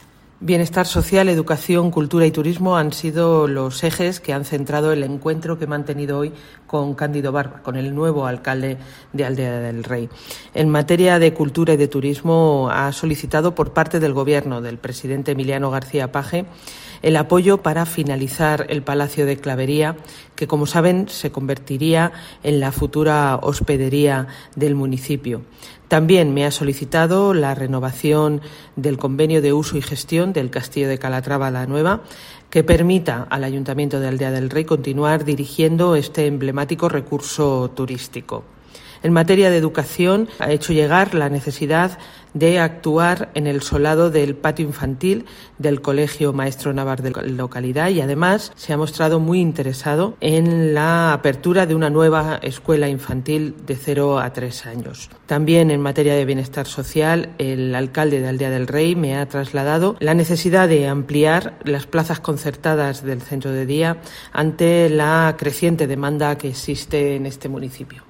Olmedo-alcalde de Aldea del Rey